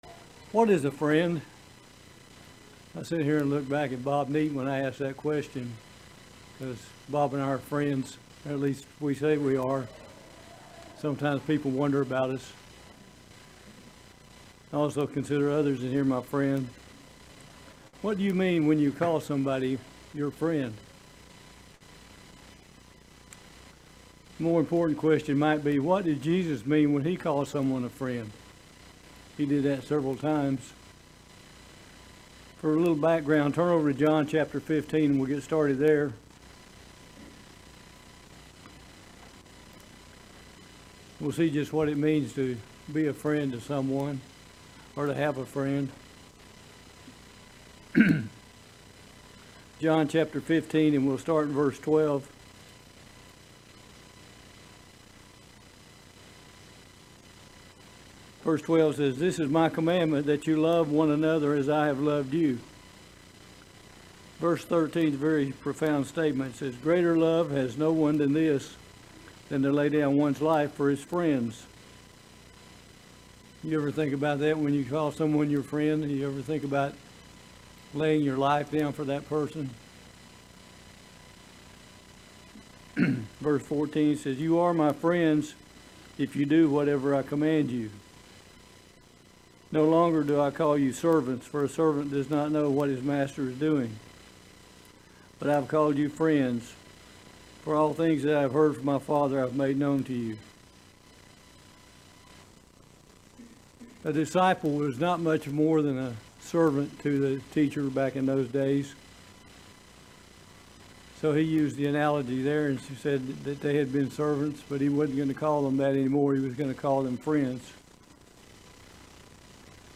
Join us for this video Sermon on "What is a Friend". What does the bible say about friendship?